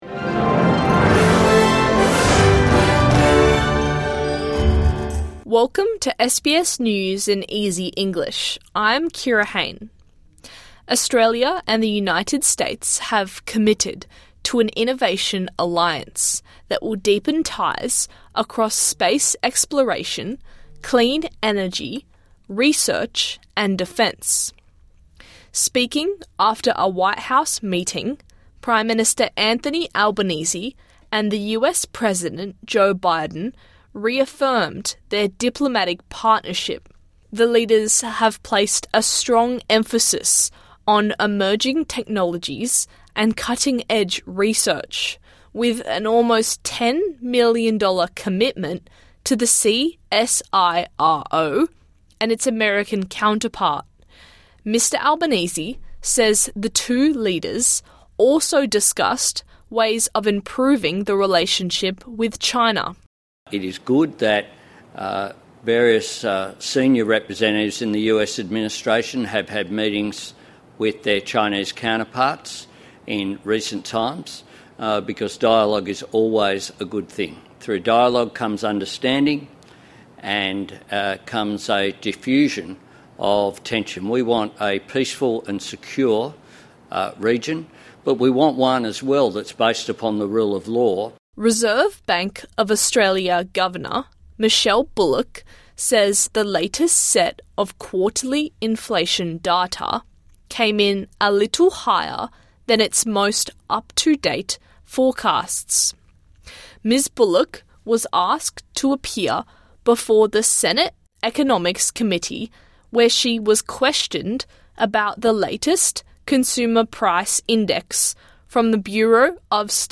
A daily 5 minute news wrap for English learners and people with disability.